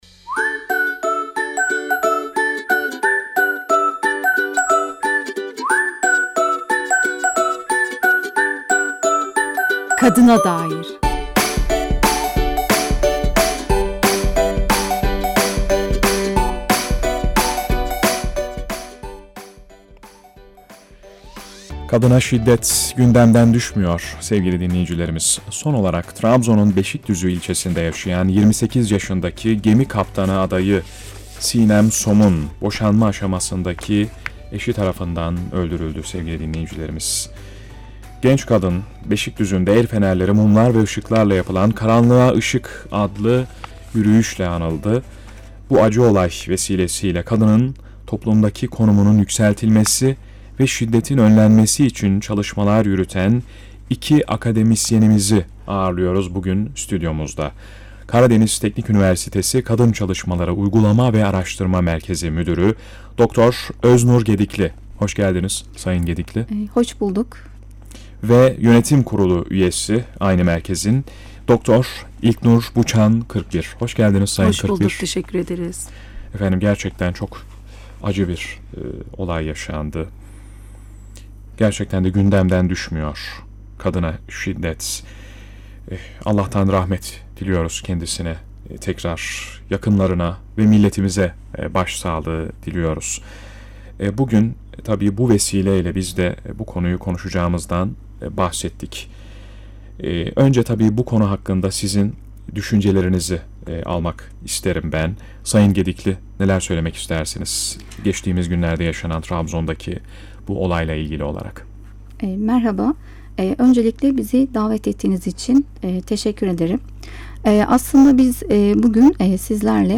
Radyo Söyleşisi | Kadın ve Aile Çalışmaları Uygulama ve Araştırma Merkezi